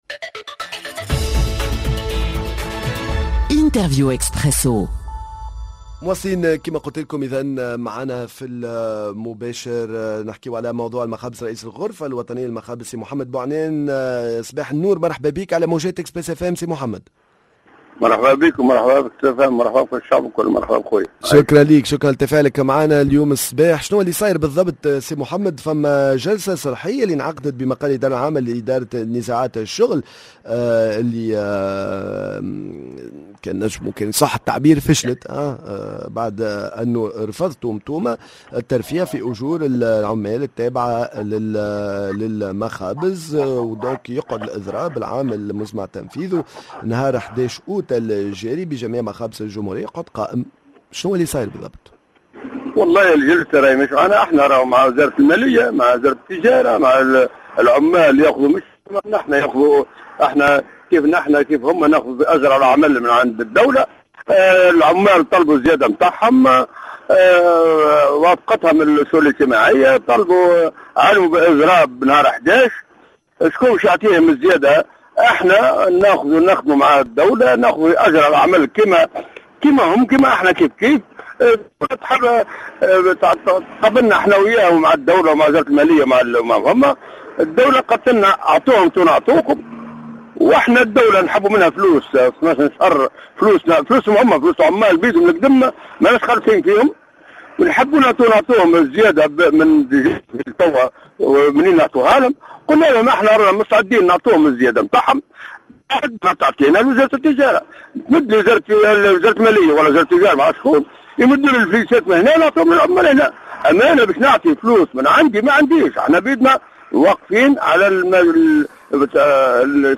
تونس دون خبز يوم 11 أوت 2022 ؟ معانا عبر الهاتف